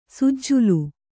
♪ sujjulu